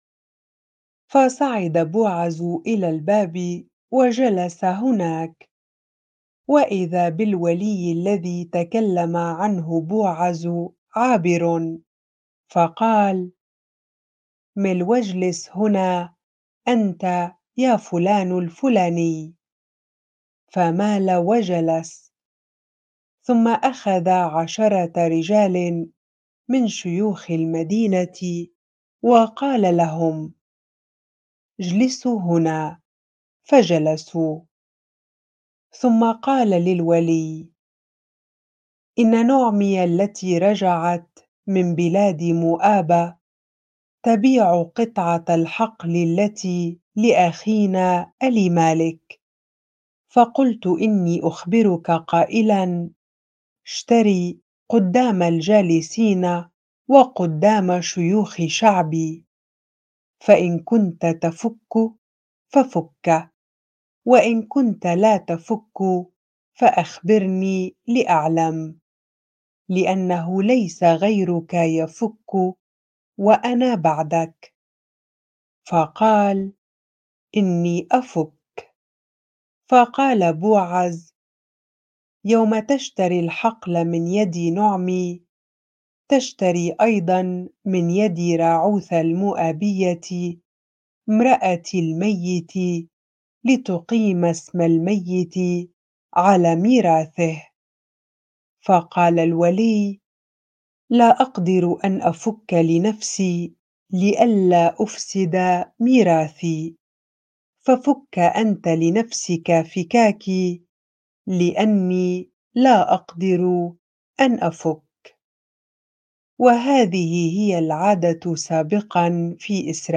bible-reading-Ruth 4 ar